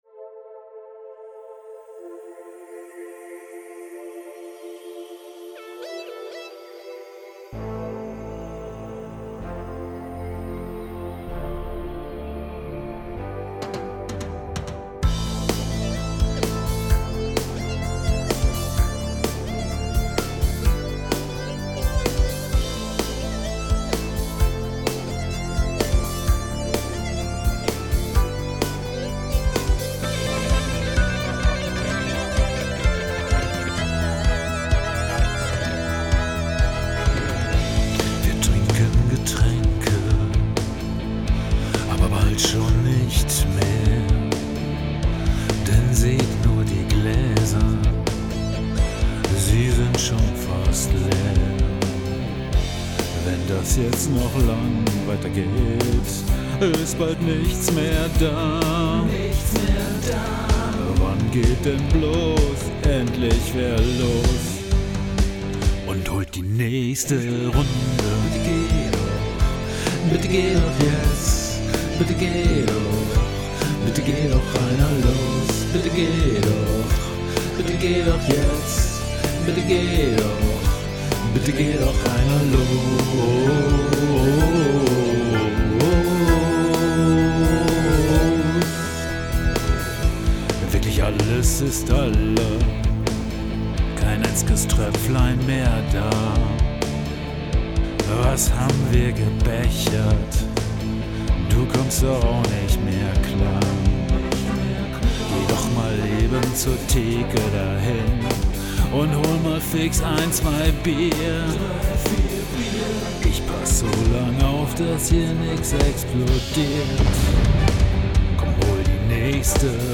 Nicht schön, aber mehrstimmig, und das mit nur einer Person.